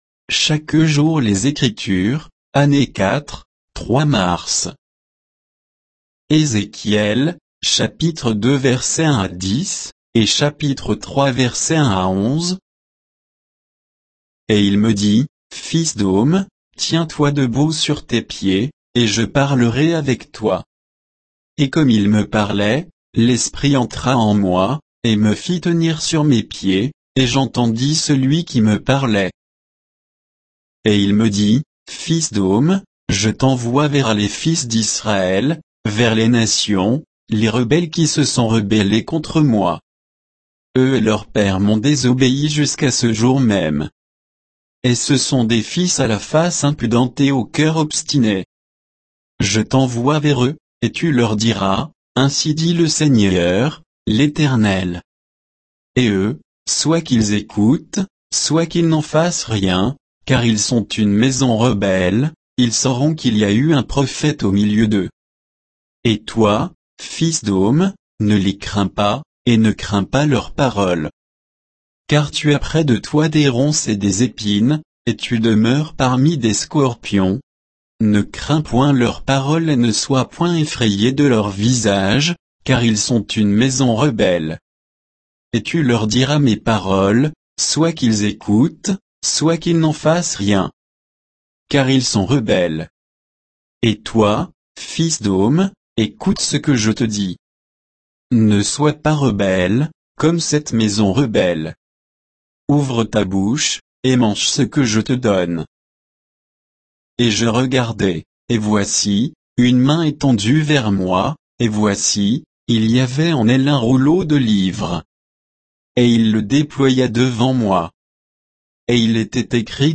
Méditation quoditienne de Chaque jour les Écritures sur Ézéchiel 2, 1 à 3, 11